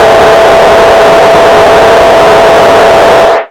RADIOFX  5-R.wav